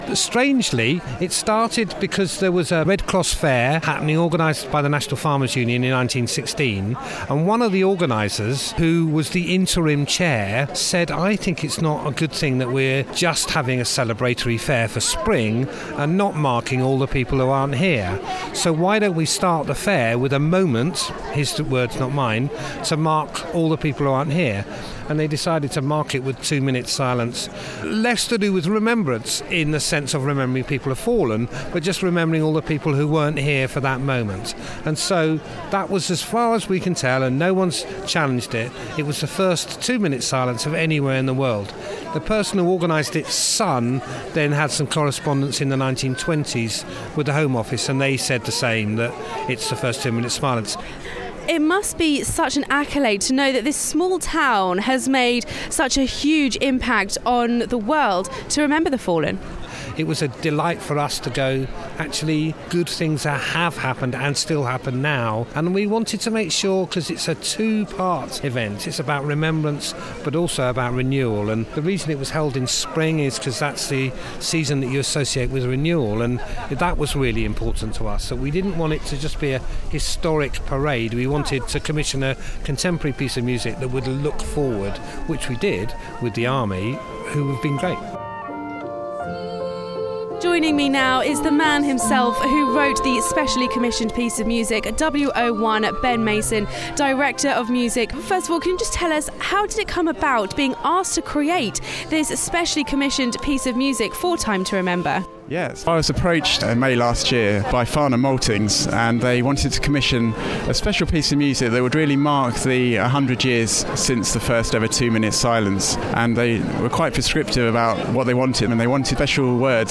Back in May 1916 the town of Farnham in Surrey held the world’s first ever two minute silence. 100 years on, the community came together to mark the historic occasion with an event called Time to Remember.